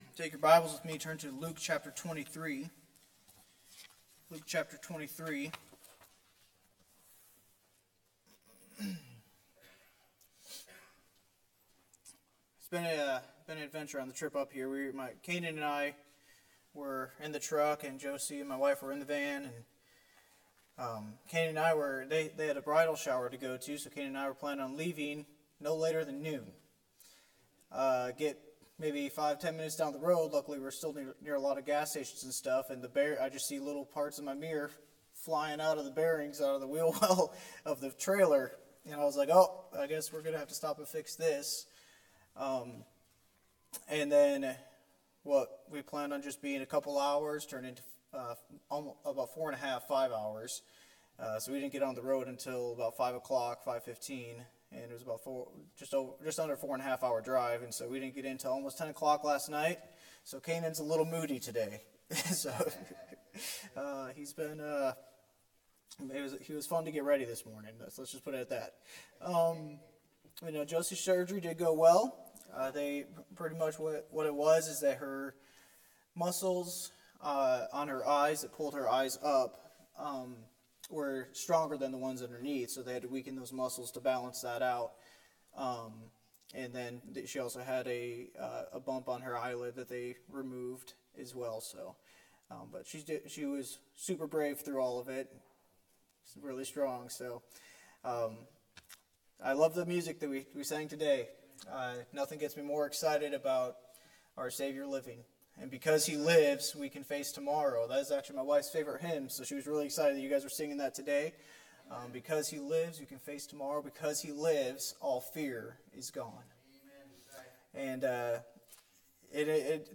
from the pulpit of Bethlehem Baptist Church in Viroqua, WI.